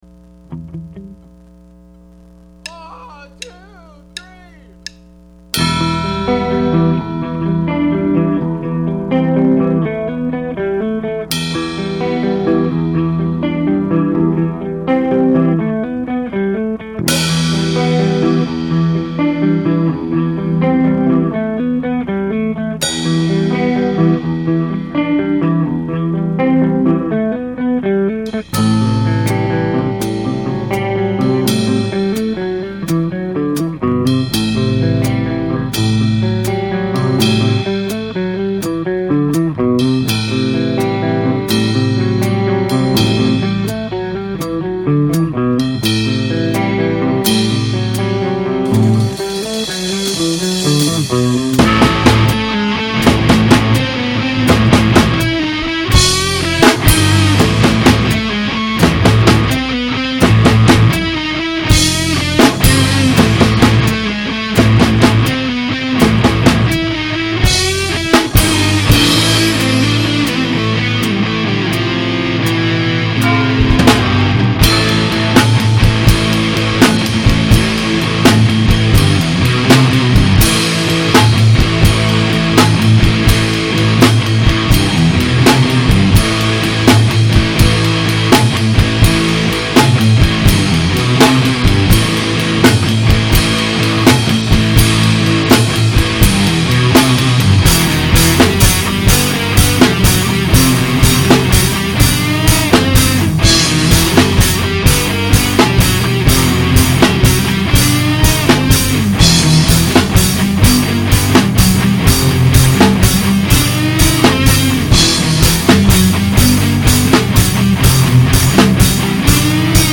Then there was Terrenon, my long-lived but less-than-prolific metal solo project.